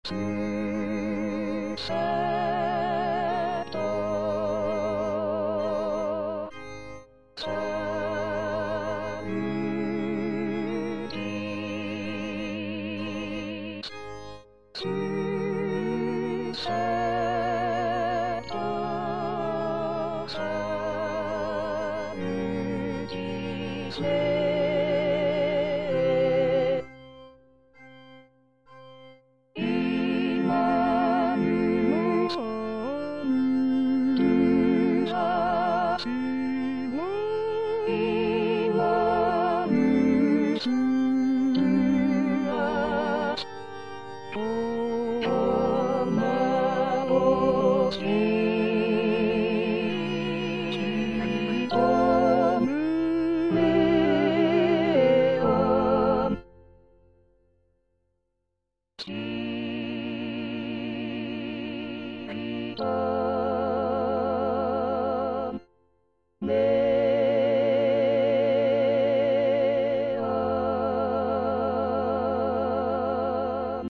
Parole 7: Pater, in manus tuas        Prononciation gallicane (à la française)
Tutti